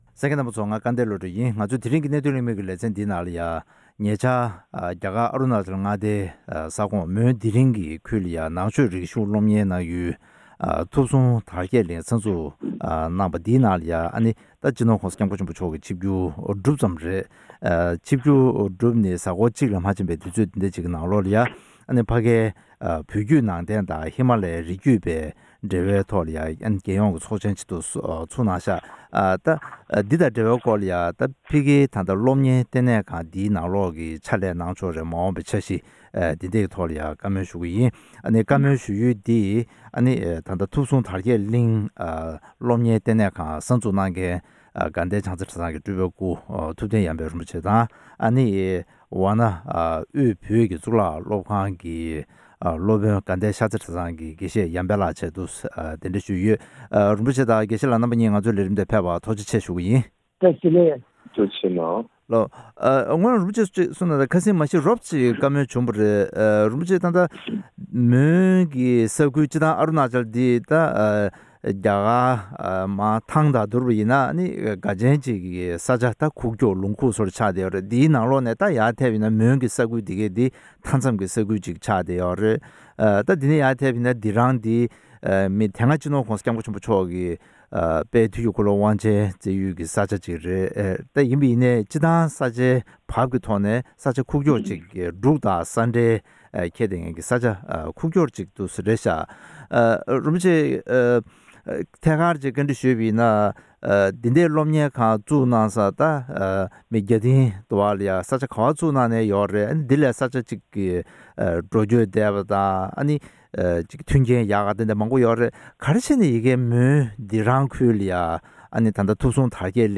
མོན་སྡི་རང་ས་གནས་སུ་ཡོད་པའི་ཐུབ་གསུང་དར་རྒྱས་གླིང་ནང་ཆོས་སློབ་གཉེར་ཁང་གི་ཕྱག་ལས་གནང་ཕྱོགས་དང་མ་འོངས་པའི་འཆར་གཞི་ཐད་གླེང་མོལ།